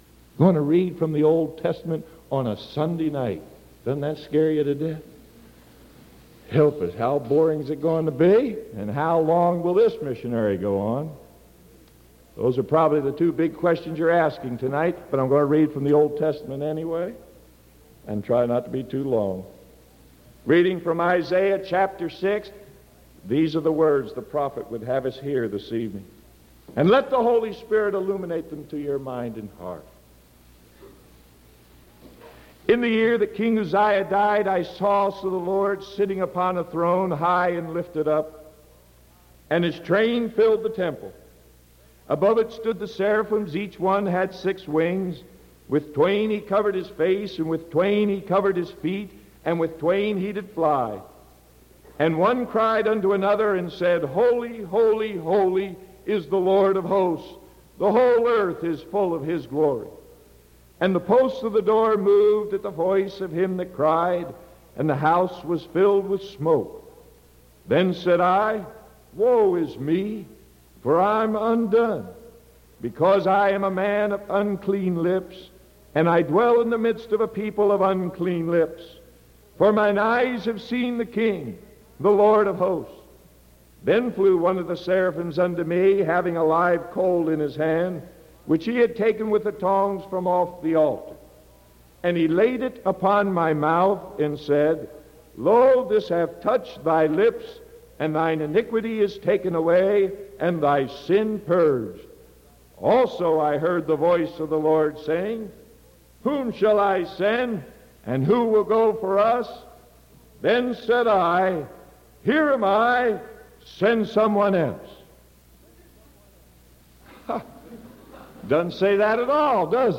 Sermon November 17th 1974 PM